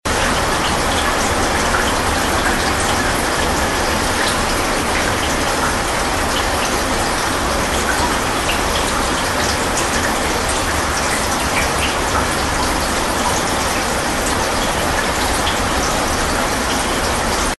Шум природы: сильный ливень